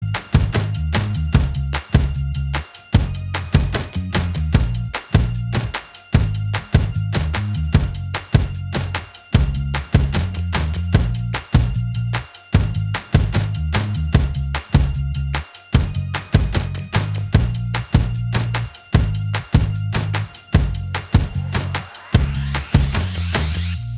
Minus All Guitars Pop (2010s) 3:27 Buy £1.50